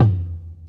Original creative-commons licensed sounds for DJ's and music producers, recorded with high quality studio microphones.
Loudest frequency: 225Hz Nineties Deep Reggae Tome Drum Sample F Key 27.wav .WAV .MP3 .OGG 0:00 / 0:01 Royality free tom tuned to the F note. Loudest frequency: 146Hz
nineties-deep-reggae-tome-drum-sample-f-key-27-l4T.wav